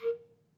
Clarinet / stac
DCClar_stac_A#3_v1_rr2_sum.wav